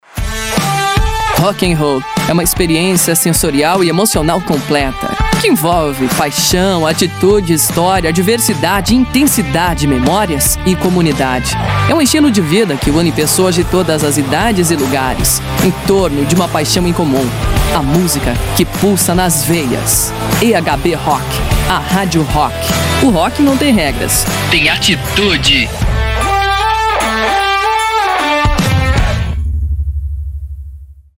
Uma voz feminina, madura e profunda, declama um manifesto que toca na ferida emocional de quem realmente vive o gênero.
Para dar vida a esse manifesto, a produção da rádio optou por uma estética pós-moderna. Uma trilha minimalista, que serve de “cama” para uma voz feminina de 40 e poucos anos. Não é uma locução publicitária comum; é a voz da maturidade de quem viveu a transição do papel para o digital sem perder a essência.